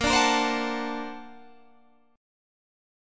Bb+M9 Chord
Listen to Bb+M9 strummed